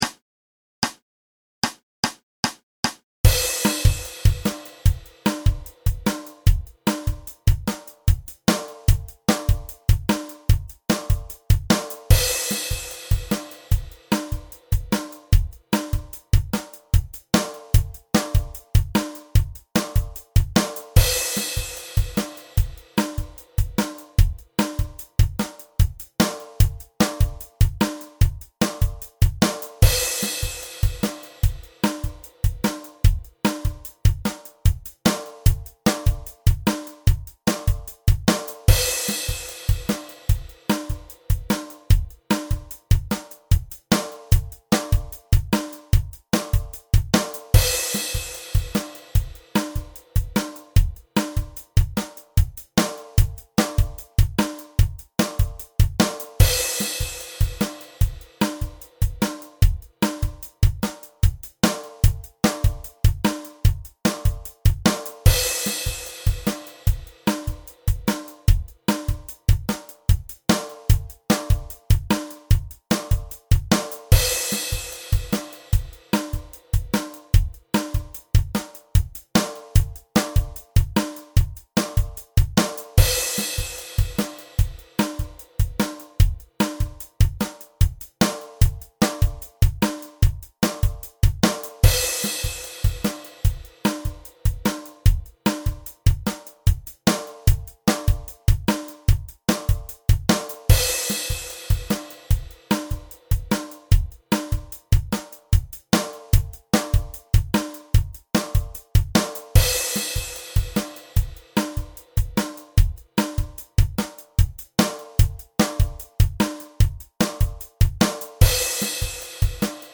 Drum Track